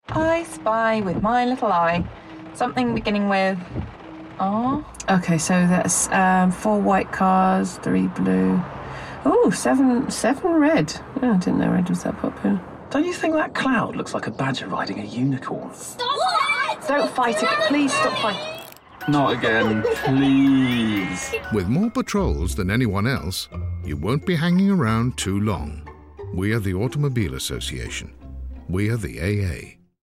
The campaign advertises the tools the company offers to help drivers in distress. In the campaign, we hear an assortment of humorous excerpts overheard in cars in moments of crisis, whether those involved have broken down, lost their keys or filled up with the wrong sort of fuel.
It sounds like a fun old recording session at Clearcut Sound, and that energy comes through in the performances.
No shouty messages.
Sound studio: Clearcut Sound